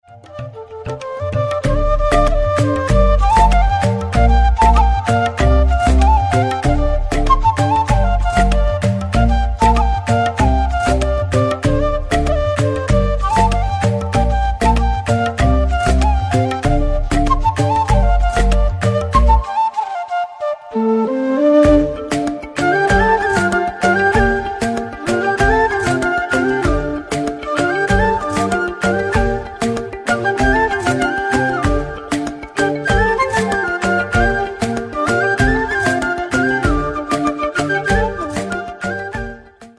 Categoría Clasicos